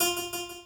harp16-04.ogg